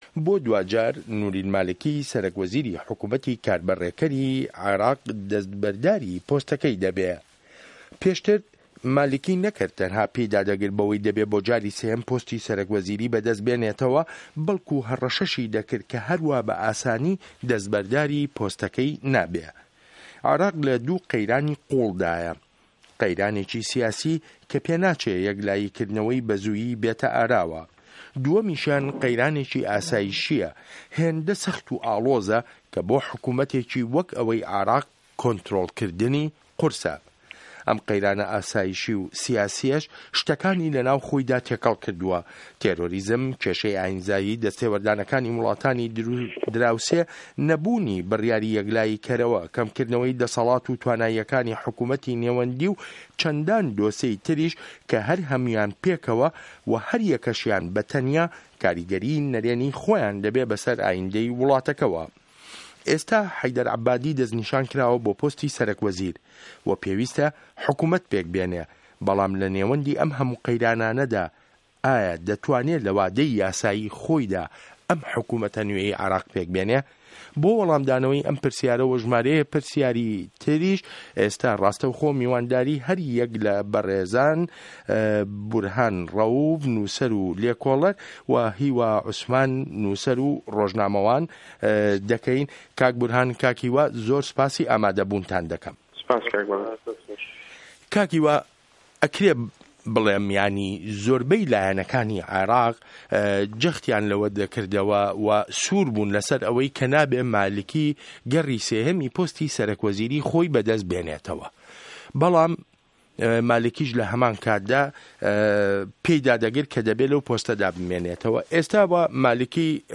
مێزگرد: عێراقی سه‌روه‌ختی عه‌بادی